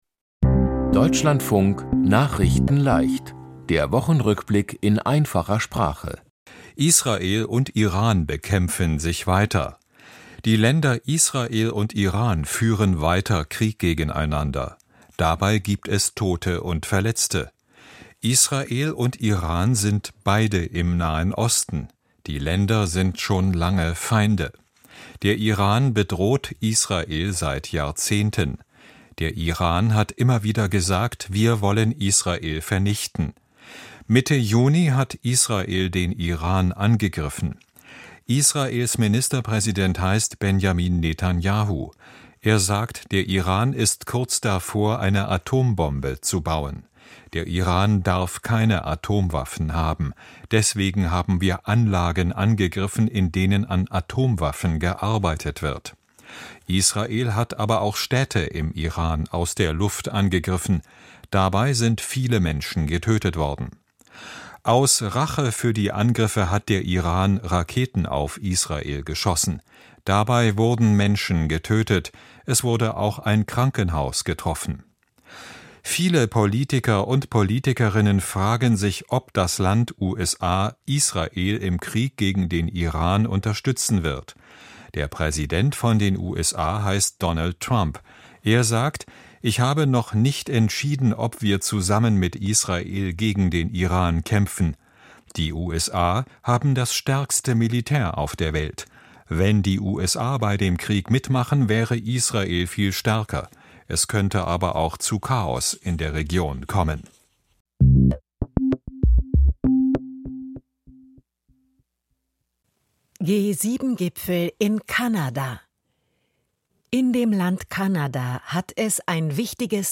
Der Wochen-Rückblick in Einfacher Sprache